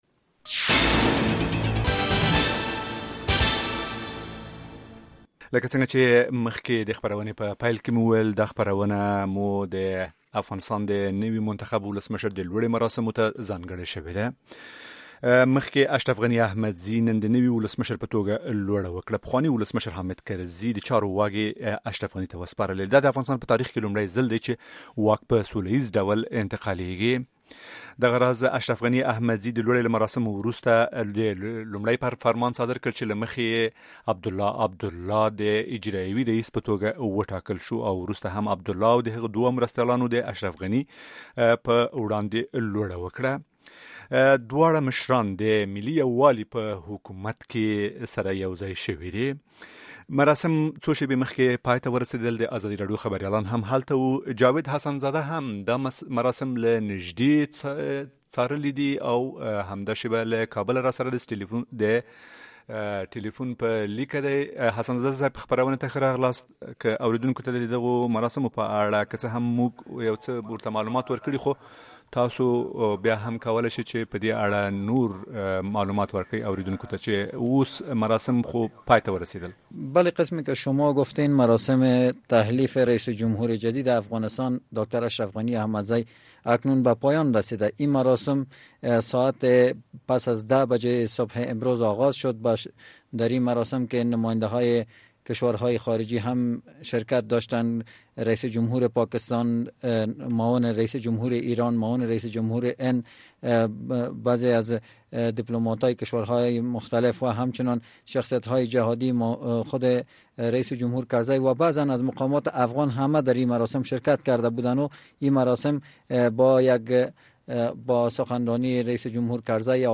در این برنامهء خاص هریک محترم در مورد اظهارات رییس جمهور احمدزی صحبت کرده، به پرسش های شنونده گان رادیو آزادی پاسخ دادند.